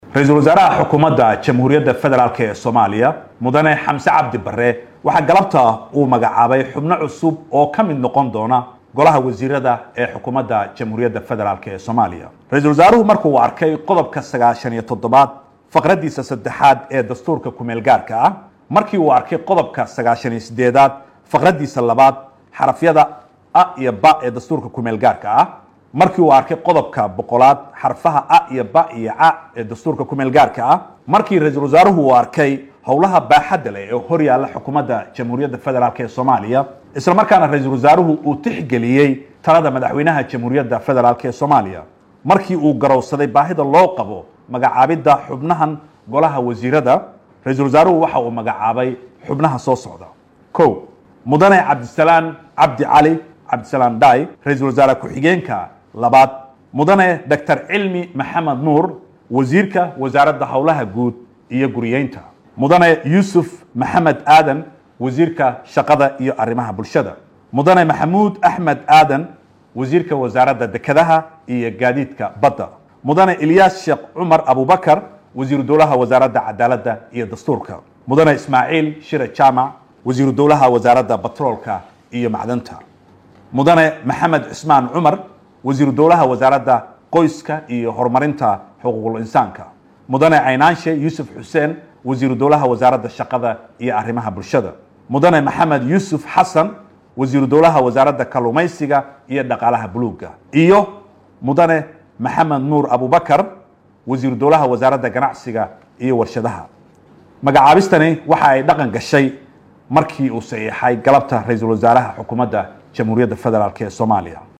Afhayeenka xukuumadda Soomaaliya Farxaan Maxamad Jimcaale ayaa warbaahinta u akhriyay xubnaha wasiirrada cusub.
Afhayeenka-xukuumadda-Soomaaliya.mp3